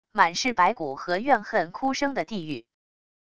满是白骨和怨恨哭声的地狱wav音频